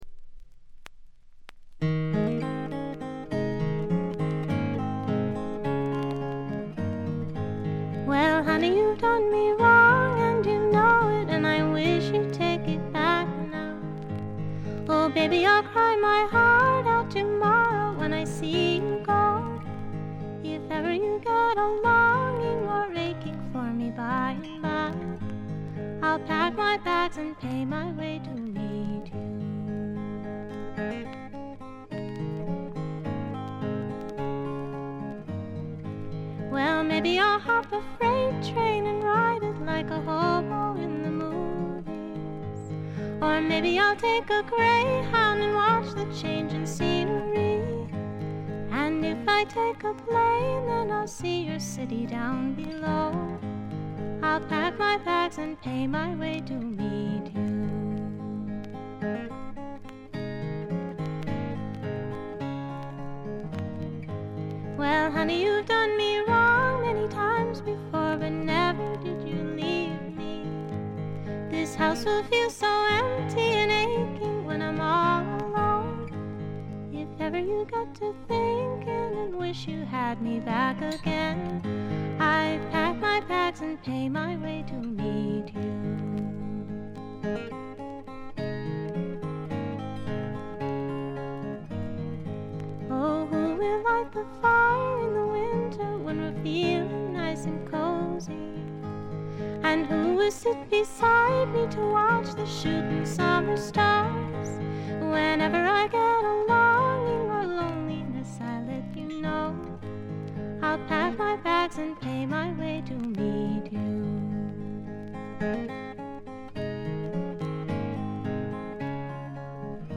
内容は自身の弾き語りを中心にした静謐でピュアで美しいフォーク・アルバムです。
試聴曲は現品からの取り込み音源です。
Vocals, Guita, Psalteryr